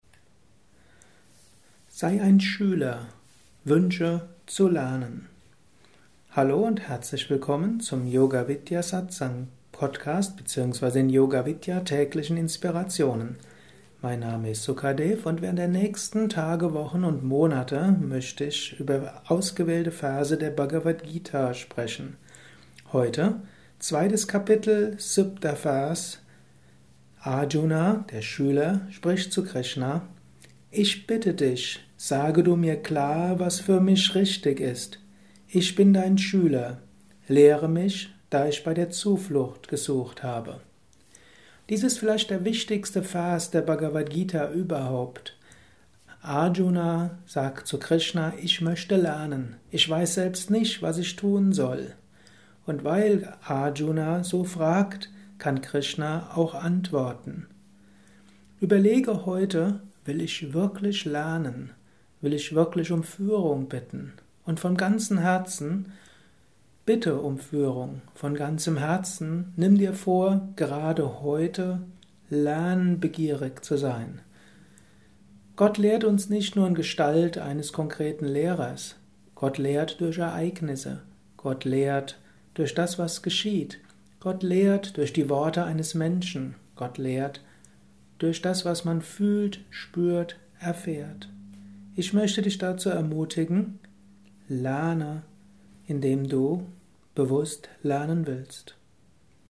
Dies ist ein kurzer Vortrag als Inspiration